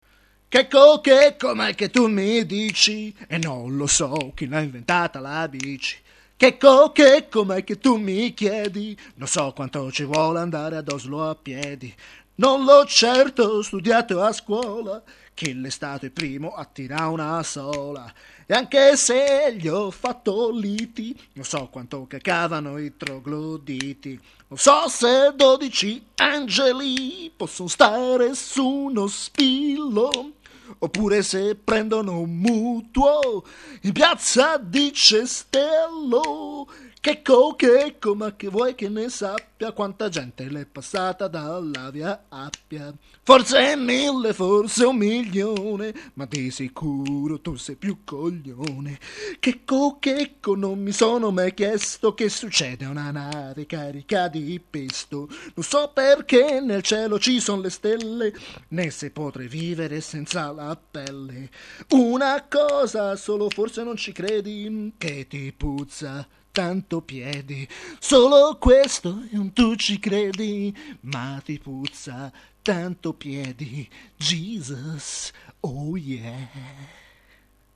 Una canzone atipica: uno spiritual.